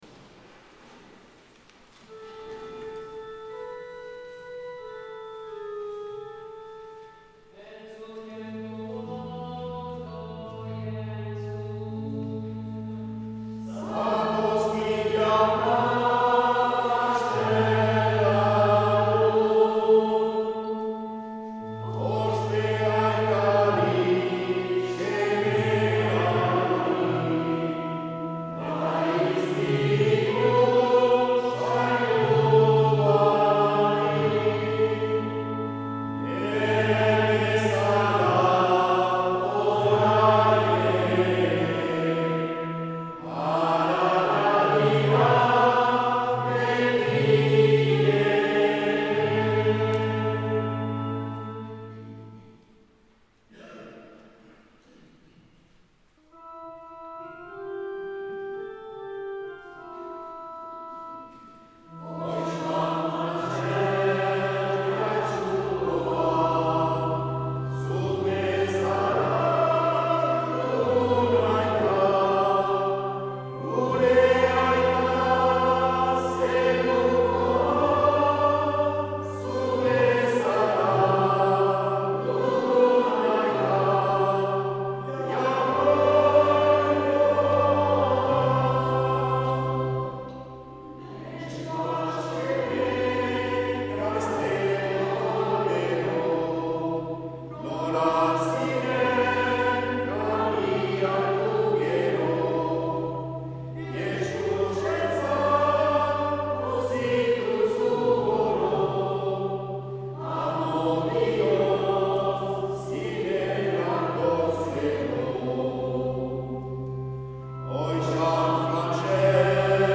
Jatsuko beila 2022. martxoaren 13an - Bezperak